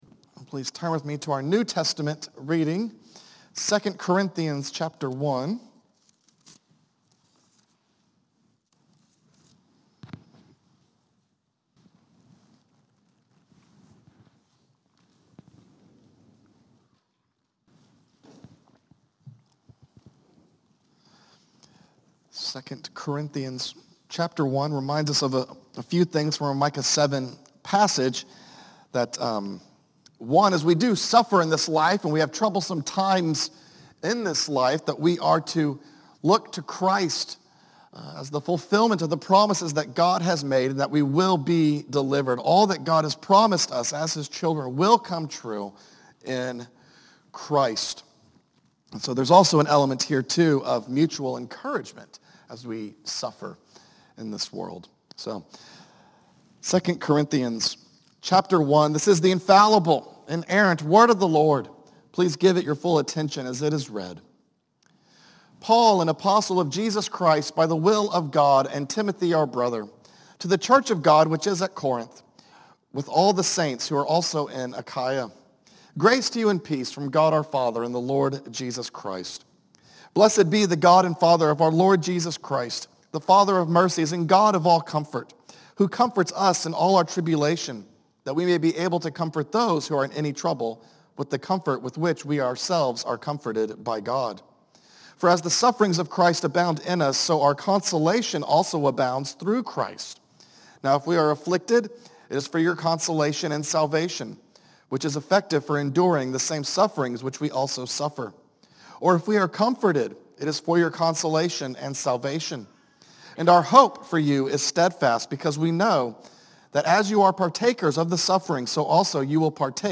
A message from the series "Micah."